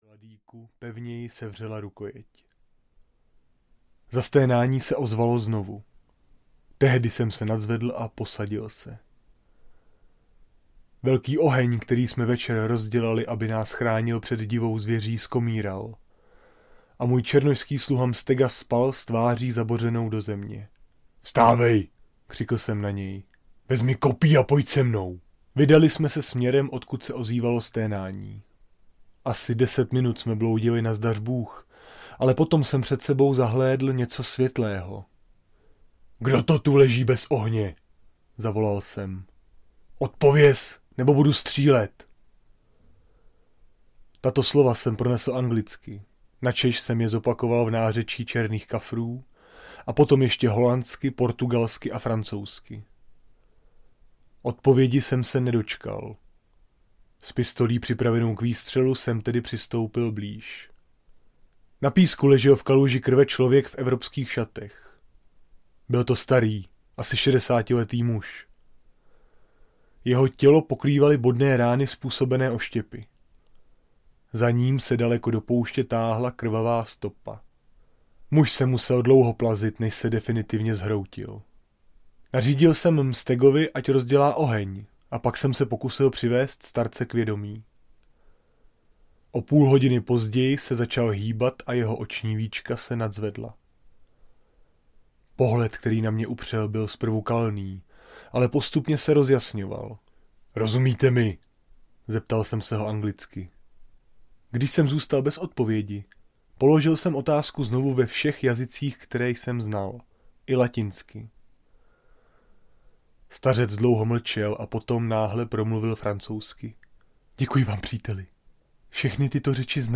Hvězdná hora audiokniha
Ukázka z knihy
hvezdna-hora-audiokniha